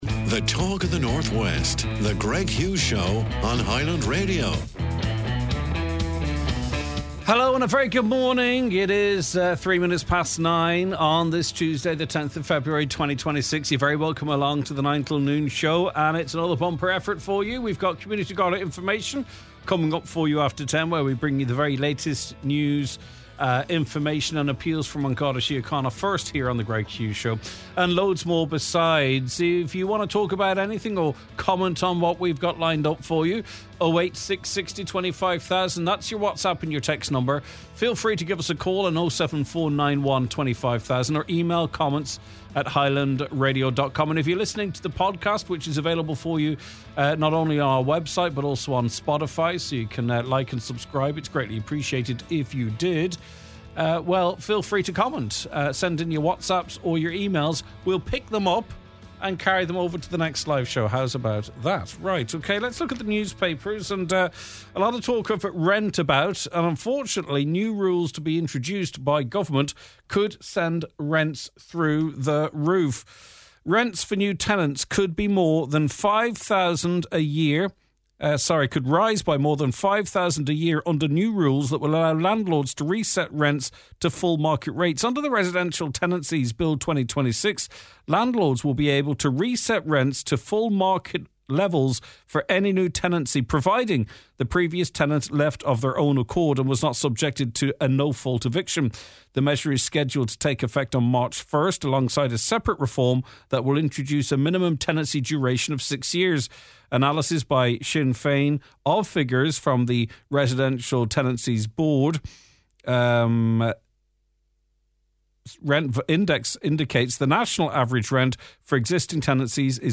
Donegal Rent Crisis: Sinn Féin Housing Spokesperson Eoin Ó Broin joins us to react to new government rental rules.